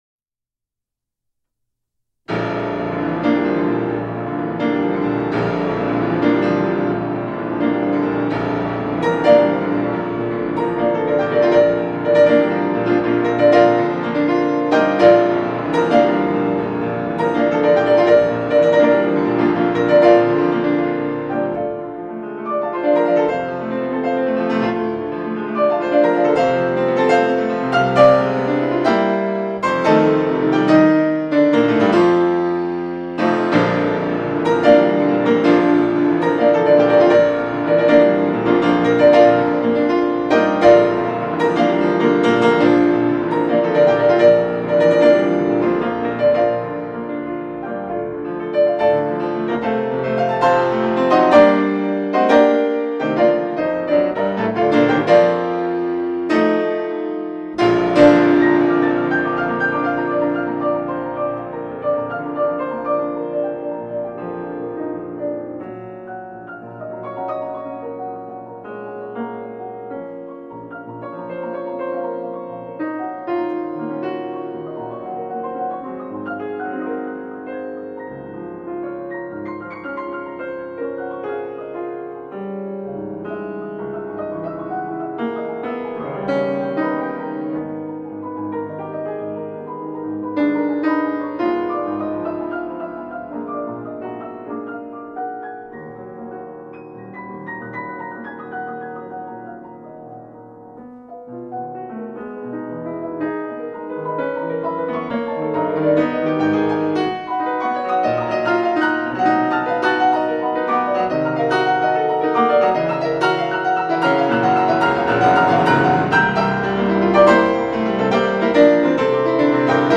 voz y piano